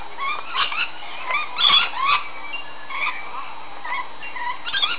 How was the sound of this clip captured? Kolas in the Healsvile Sancuary